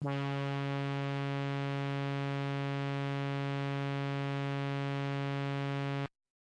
描述：通过Modular Sample从模拟合成器采样的单音。
Tag: CSharp5 MIDI音符-73 Korg的-Z1 合成器 单票据 多重采样